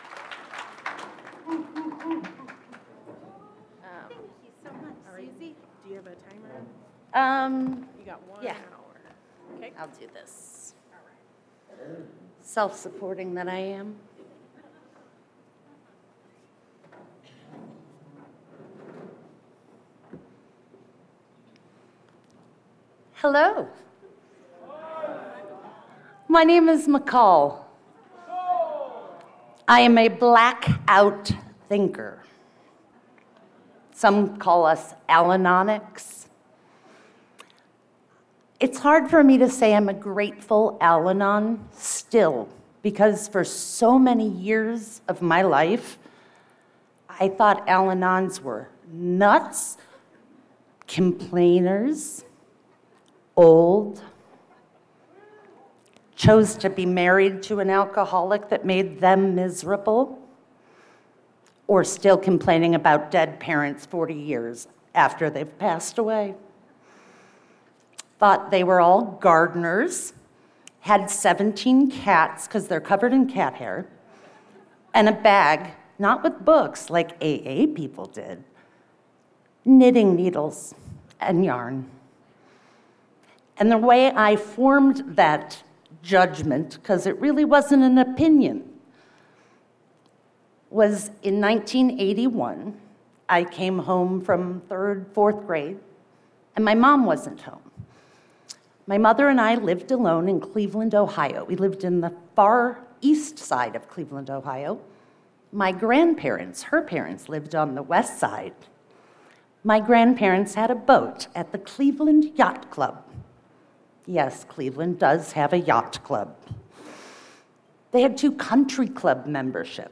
32nd Indian Wells Valley AA Roundup